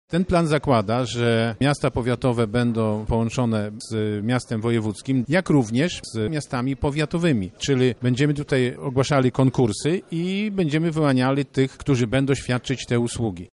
Głównym celem naszego planu jest zwiększenie liczby połączeń w komunikacji lokalnej – mówi marszałek województwa lubelskiego Sławomir Sosnowski.